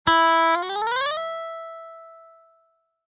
Descarga de Sonidos mp3 Gratis: guitarra a 2.